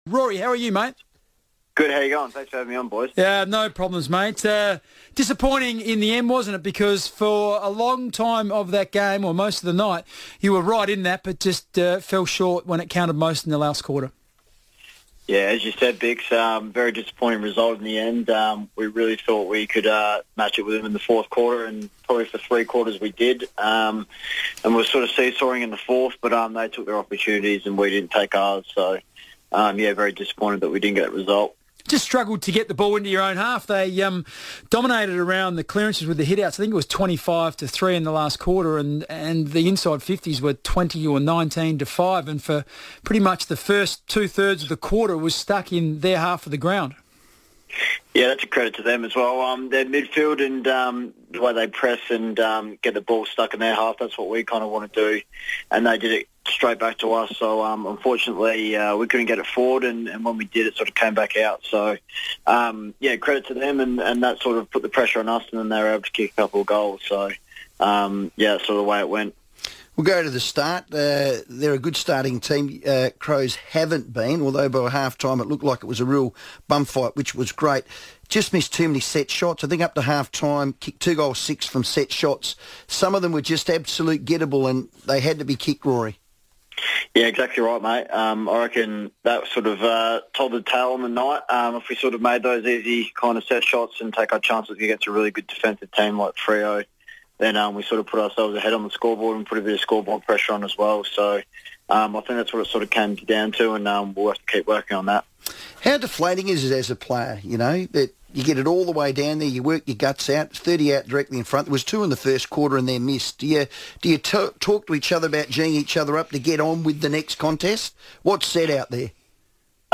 Rory Laird on FIVEaa